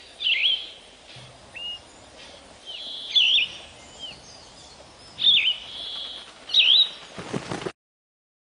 燕雀鸟鸣声